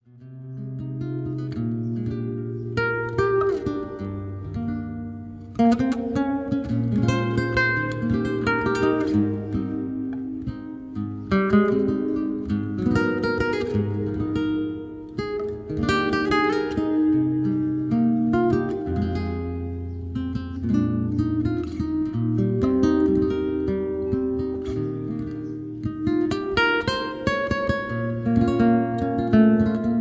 Soothing and Relaxing Guitar Music
Guitarist